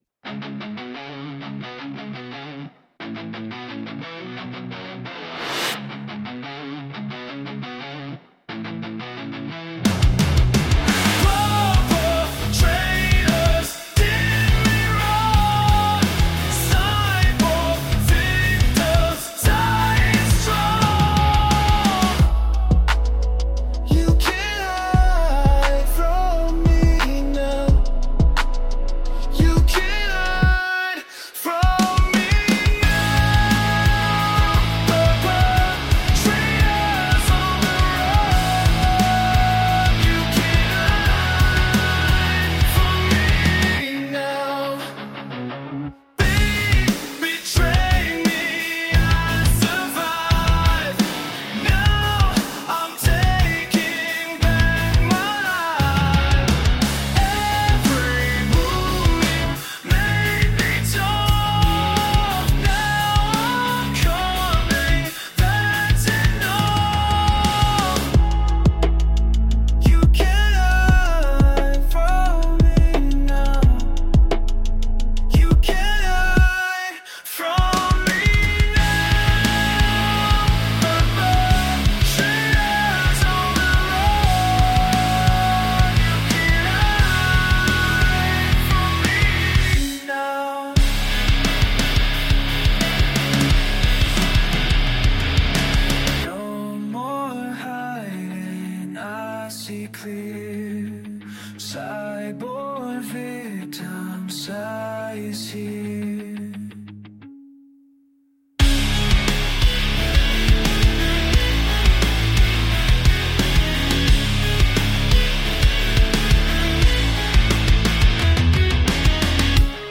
Metal Sound Base Electric Guitar Raw Riffs Big Drums